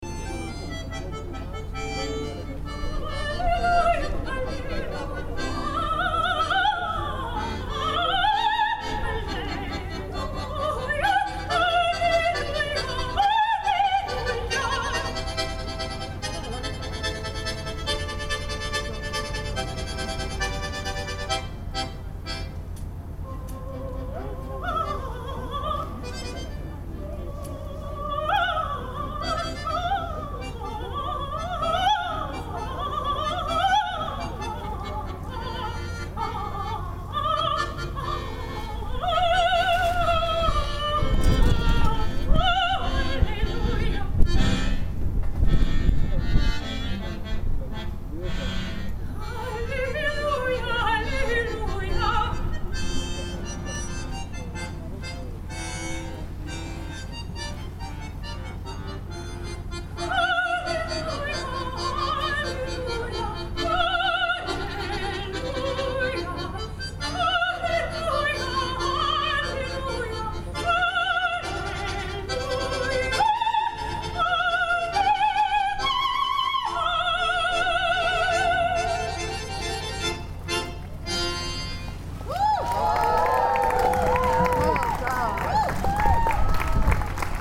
There, on a cold and drizzly afternoon, a decent amount of people turned out to see some great disability performances.
accordion
songs from some of the most well known operas